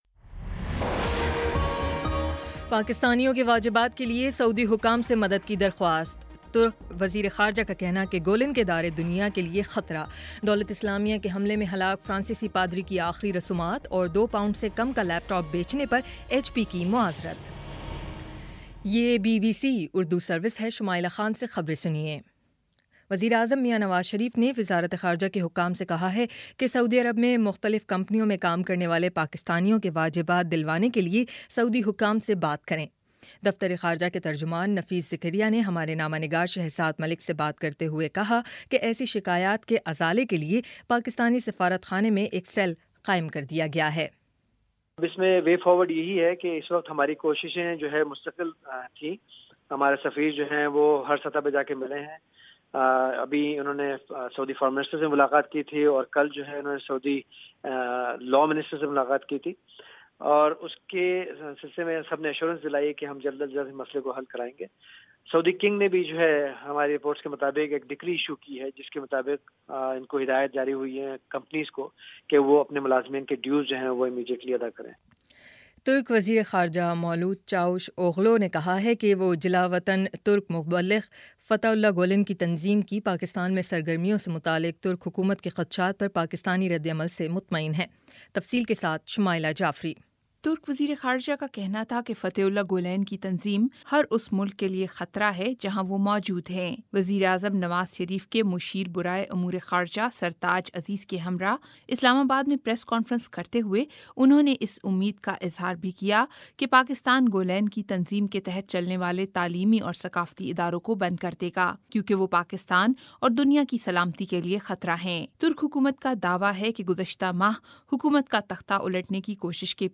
اگست 02 : شام چھ بجے کا نیوز بُلیٹن